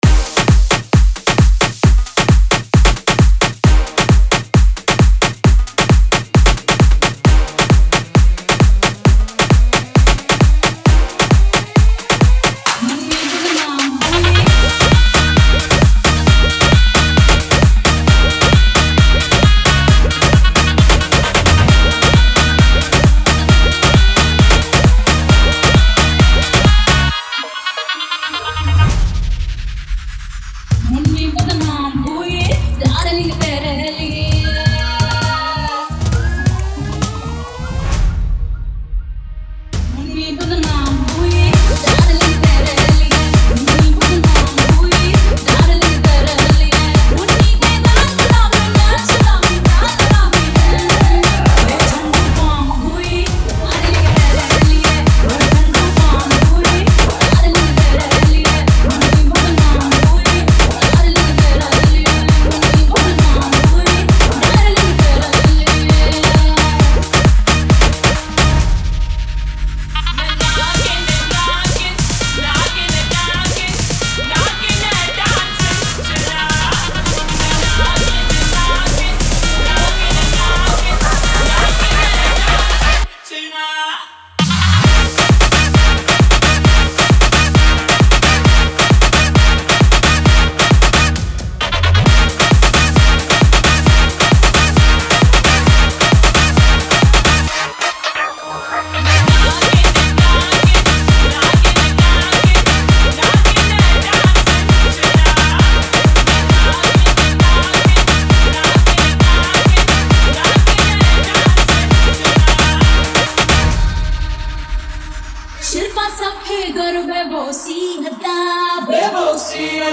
2024 Bollywood Single Remixes Song Name